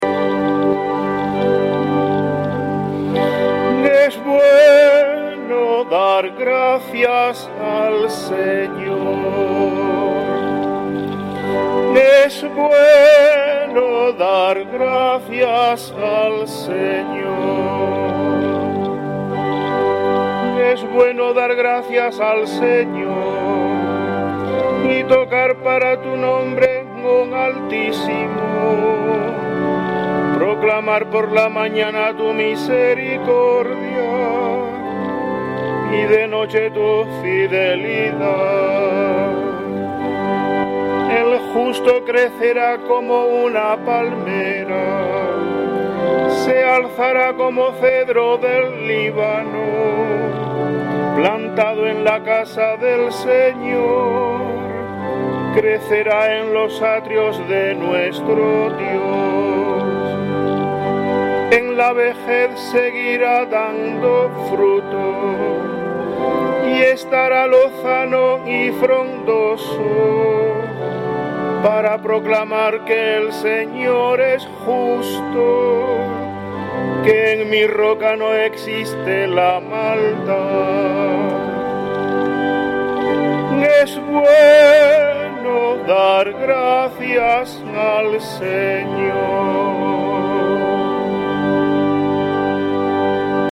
Salmo Responsorial 91/ 2-3; 13-16